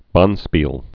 (bŏnspēl)